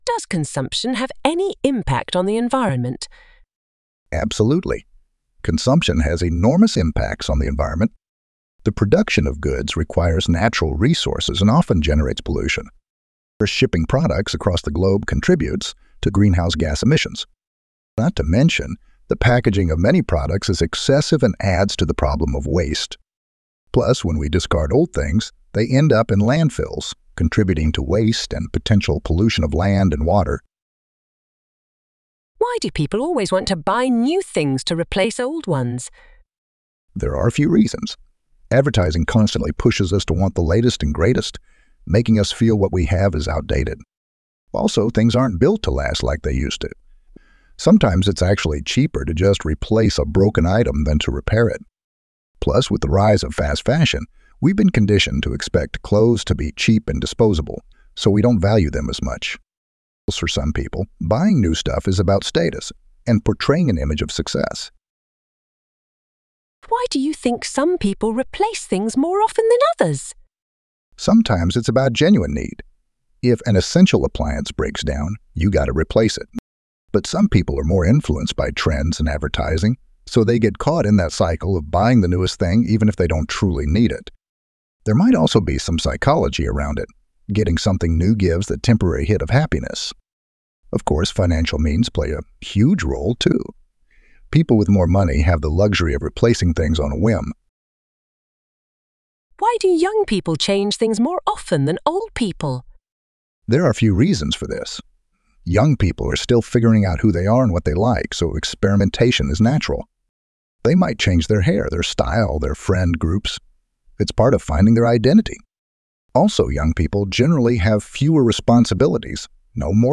Trong bài viết này, Mc IELTS chia sẻ câu trả lời mẫu band 8.0+ từ cựu giám khảo IELTS, kèm theo các câu hỏi mở rộng và bản audio từ giáo viên bản xứ để bạn luyện phát âm, ngữ điệu và tốc độ nói tự nhiên.